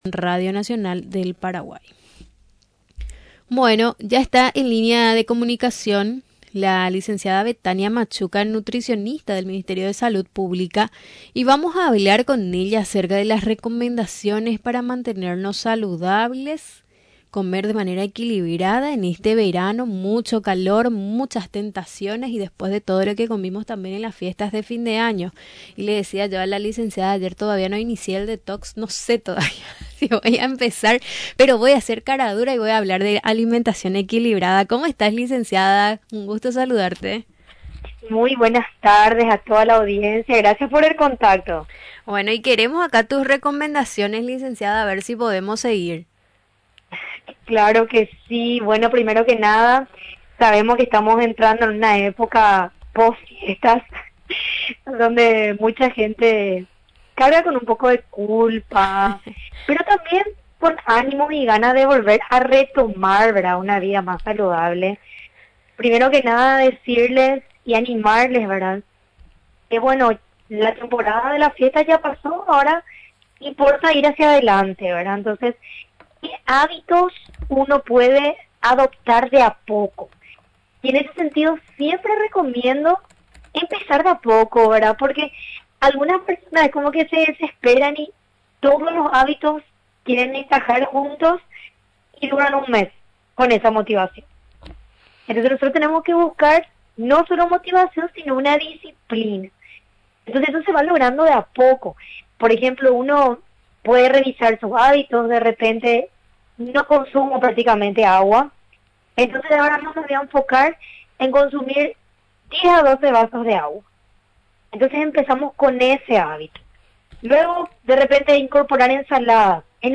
Durante la entrevista en Radio Nacional del Paraguay, explicó las bondades y los beneficios de los alimentos naturales en especial en los días de calor extremo que se tiene en nuestro país.